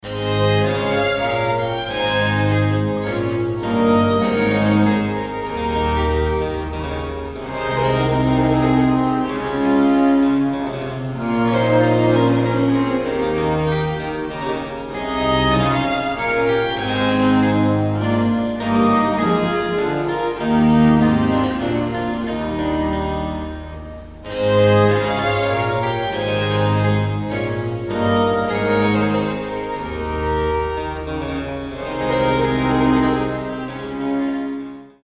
Baroque chamber ensembles
suite for violin, 2 violas & continuo in A major